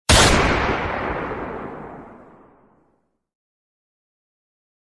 Gunshot Sound Button: Unblocked Meme Soundboard
Gunshot Sound Button Sound Effects